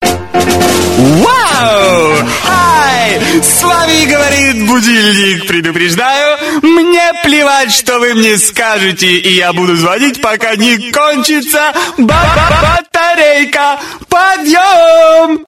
Звуки для пробуждения
Говорящий будильник WOW Привет С вами говорит будильник